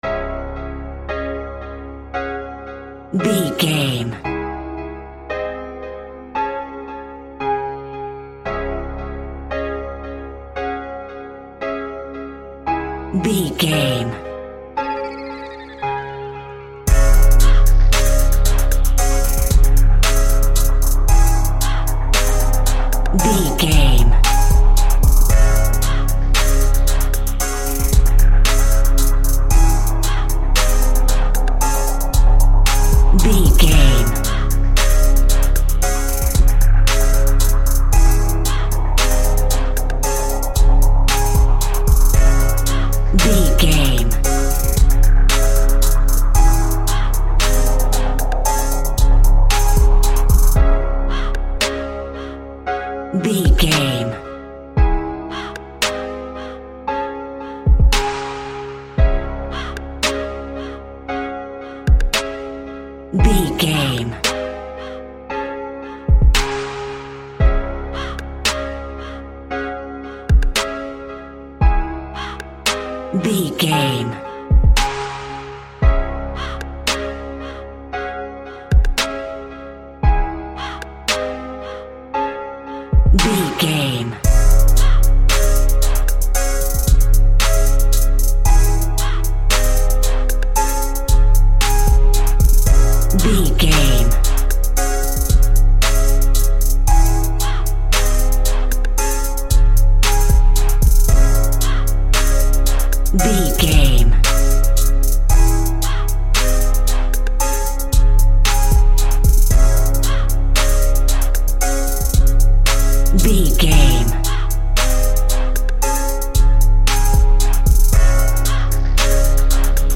Aeolian/Minor
C#
chilled
laid back
groove
hip hop drums
hip hop synths
piano
hip hop pads